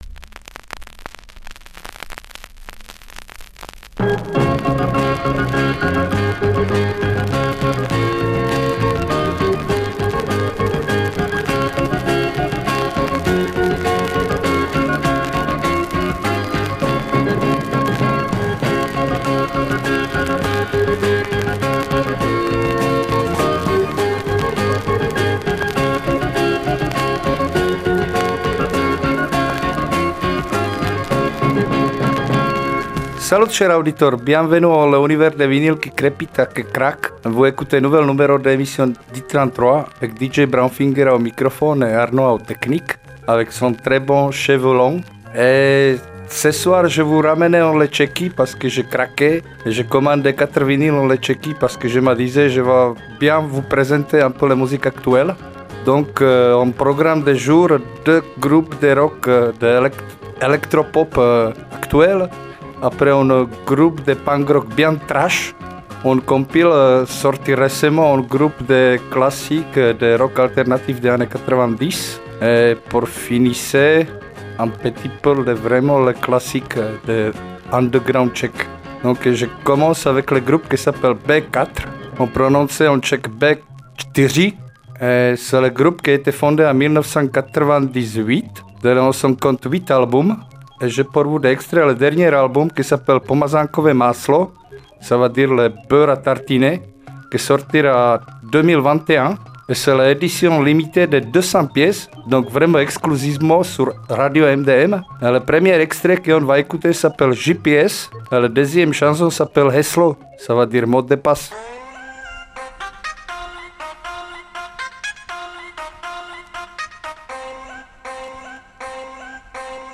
Programmation musicale éclectique, multi-générationnelle, originale, parfois curieuse, alternative et/ou consensuelle, en tous les cas résolument à l’écart des grands réseaux commerciaux.